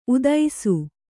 ♪ udaisu